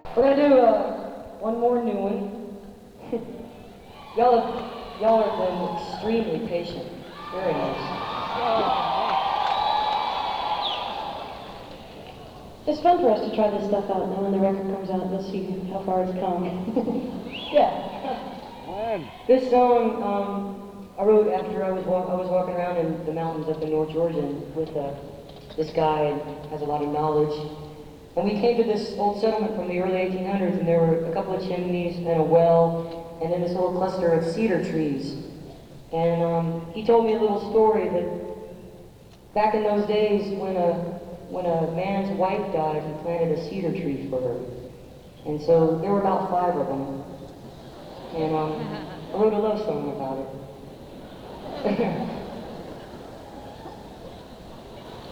(acoustic duo)
16. talking with the crowd (0:55)